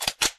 ar_charge.ogg